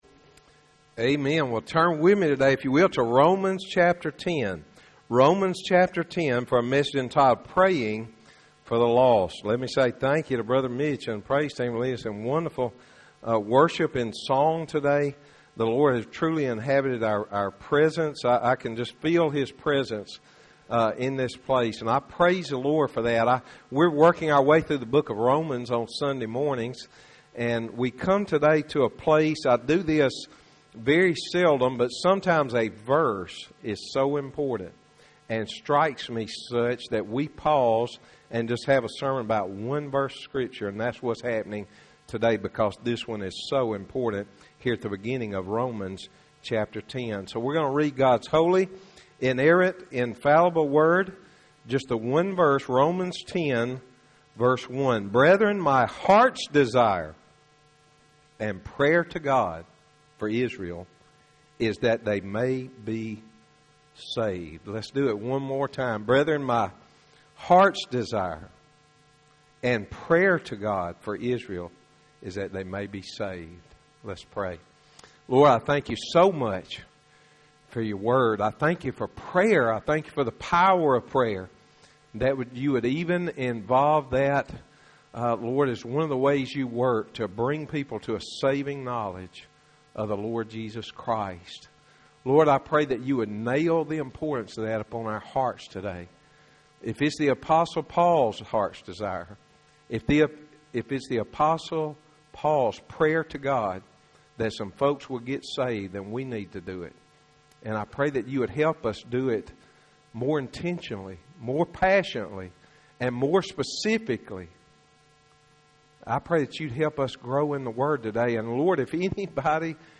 Sermons - Highland Baptist Church